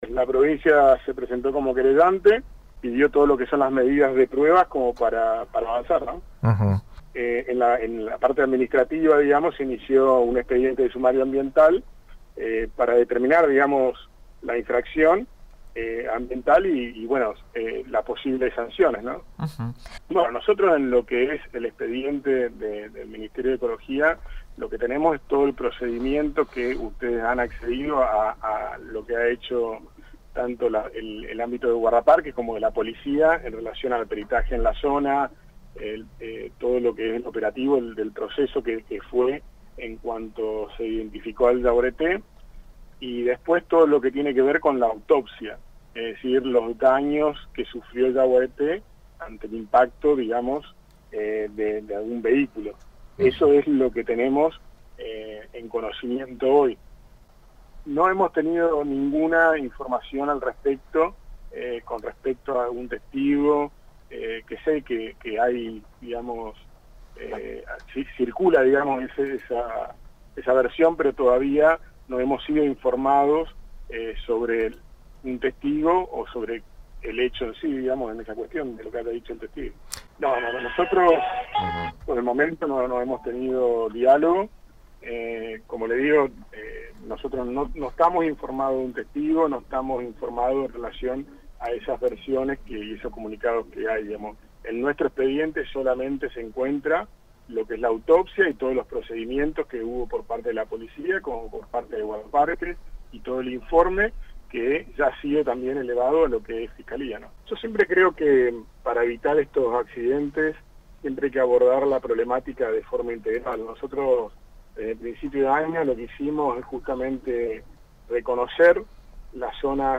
Lo aclaró el ministro de Ecología, Martín Recamán en una entrevista con la radio de PRIMERA EDICIÓN.